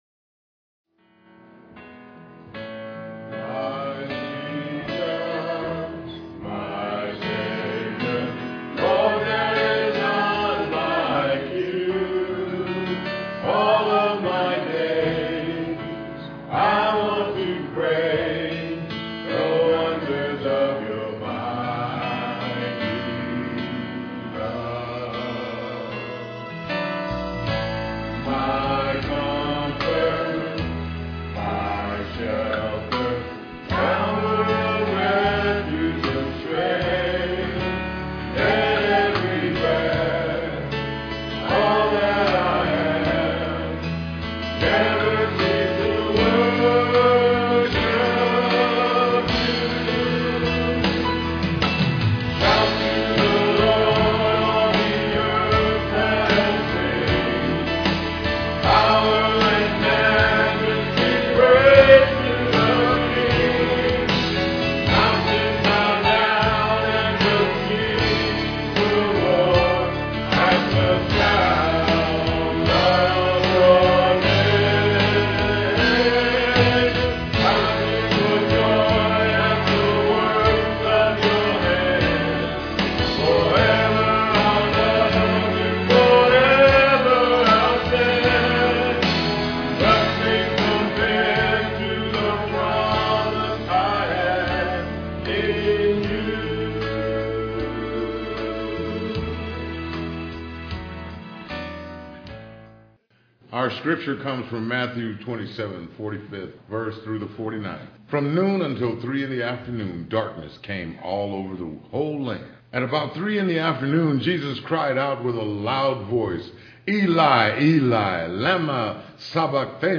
Solo
Piano offertory